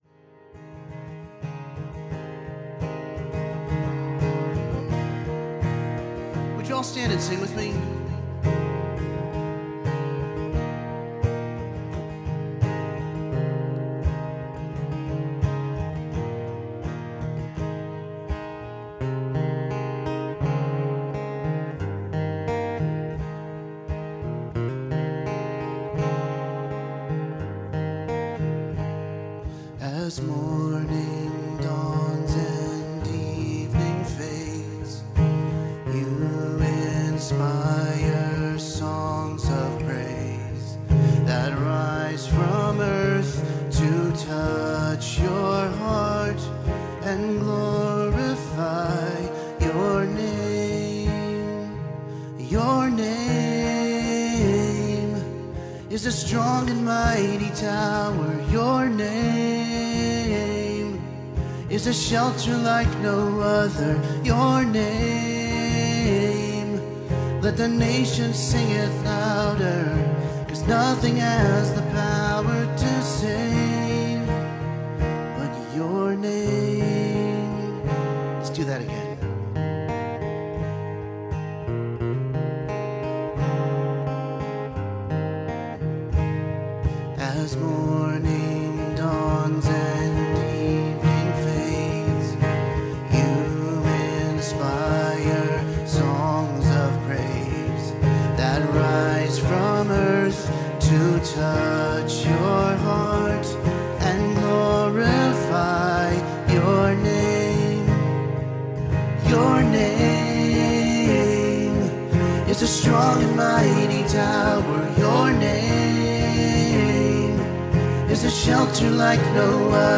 CCC Sermons Service Type: Sunday Morning